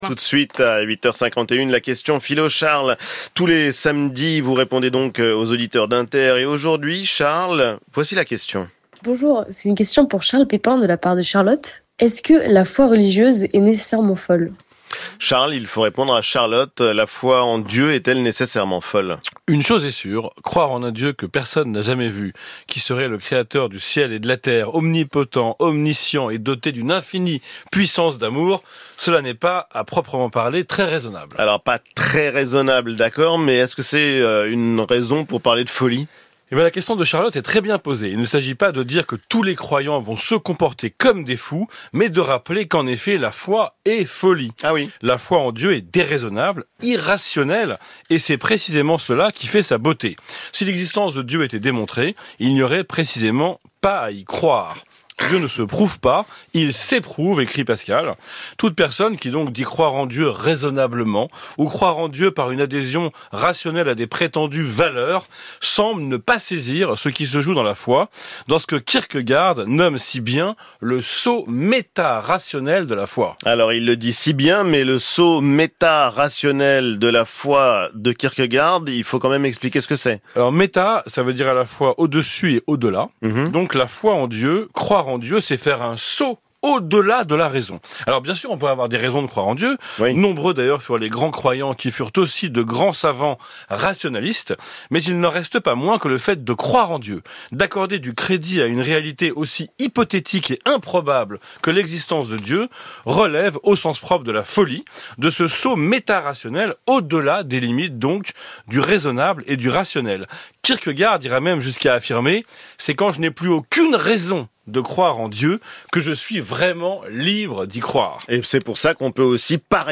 “La question philo” est une chronique animée par le philosophe Charles Pépin sur France Inter. Elle s’écoute à la radio le samedi vers 8h45, mais aussi en podcast.
Chronique du 19 avril 2025 animée par Ali Baddou (AB) et Charles Pépin (CP)